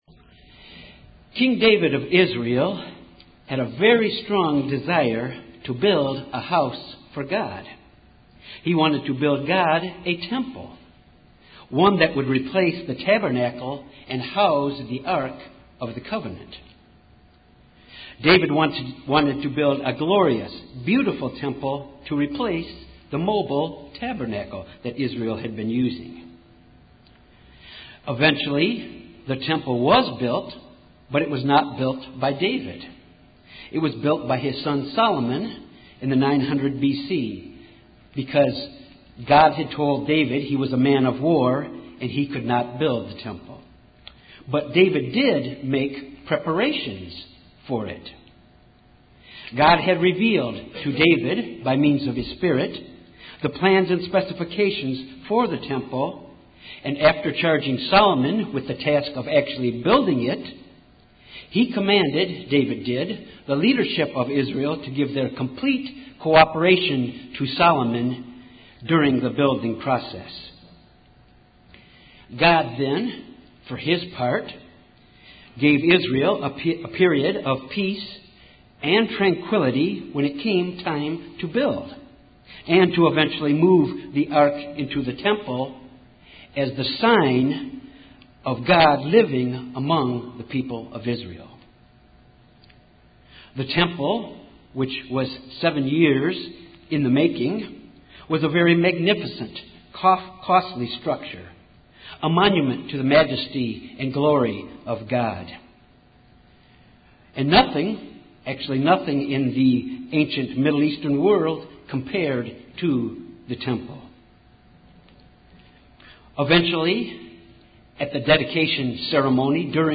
This sermon examines some of the lessons we can learn from the temple rebuilding during the days of Ezra.